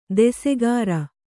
♪ desegāra